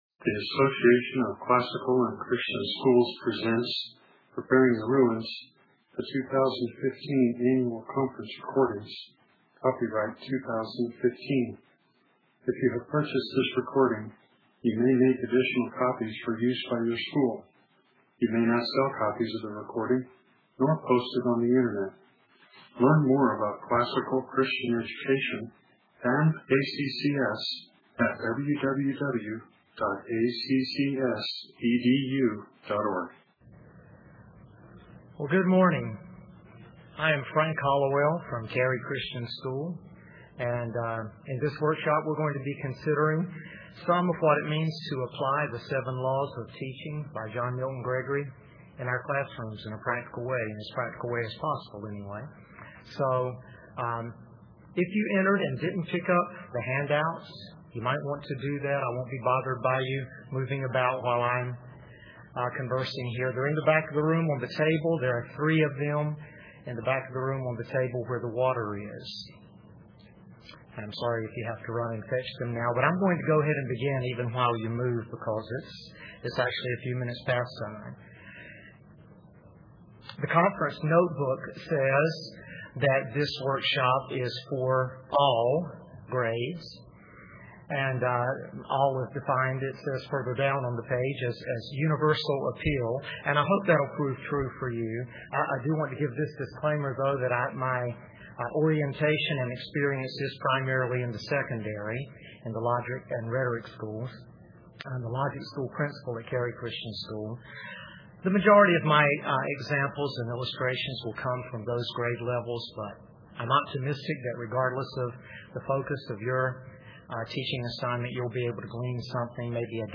2015 Workshop Talk | 1:07:30 | 7-12, General Classroom, Virtue, Character, Discipline